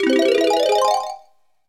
Sound effect of Morph To Normal in Super Mario Galaxy